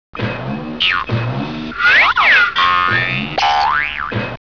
Sprongs.wav